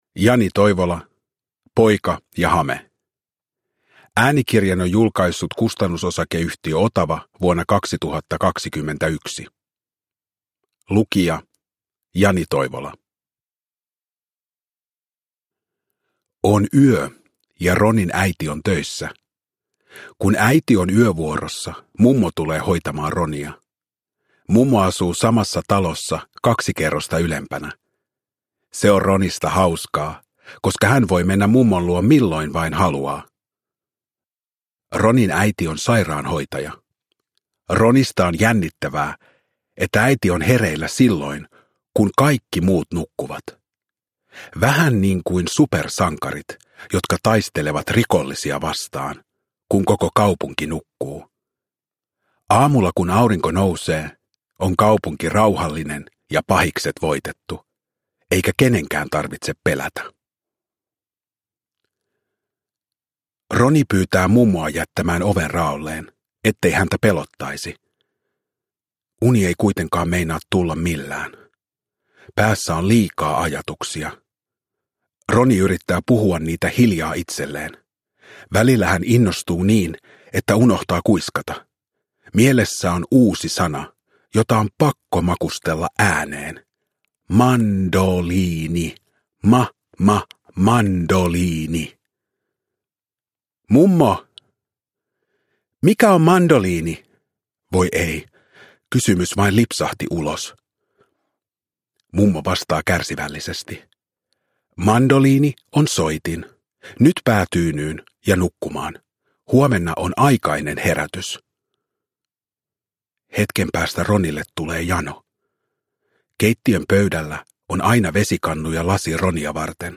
Poika ja hame – Ljudbok – Laddas ner
Uppläsare: Jani Toivola